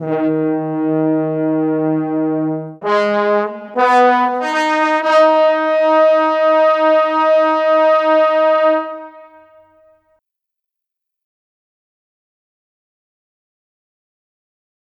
Night Rider - Horns.wav